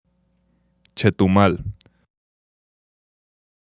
Chetumal (UK: /ˌɛtʊˈmɑːl/,[1] US: /ˌtuˈ-/,[2][3] Spanish: [tʃetuˈmal]